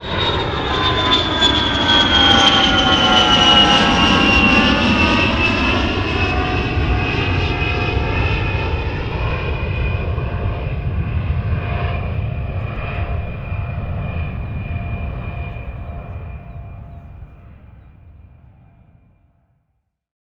airplane-sound-effect